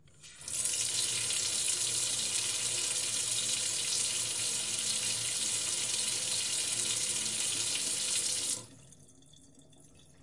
水 泄漏，小
描述：小水从水龙头漏到一碗水里。最后可以听到气泡声。 用Zoom H4n录音机录制。
Tag: 泄漏 小便 泄漏 现场记录 抽头